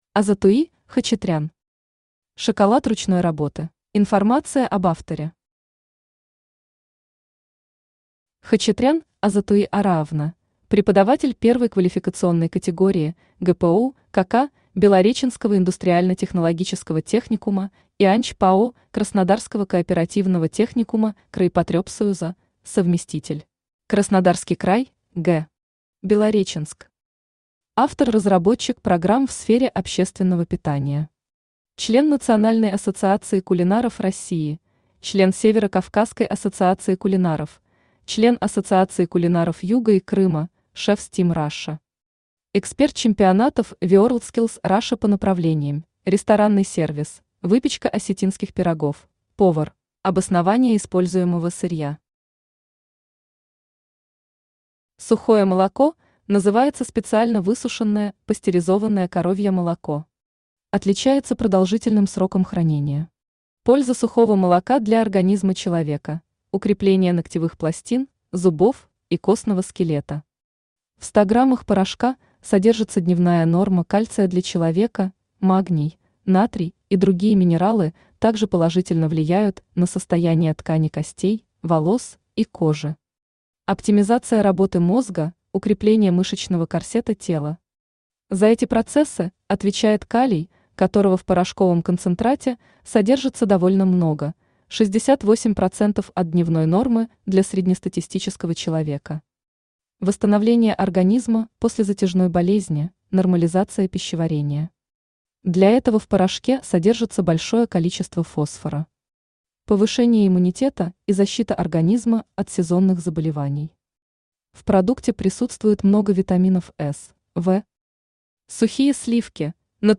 Аудиокнига Шоколад ручной работы | Библиотека аудиокниг
Aудиокнига Шоколад ручной работы Автор Азатуи Араовна Хачатрян Читает аудиокнигу Авточтец ЛитРес.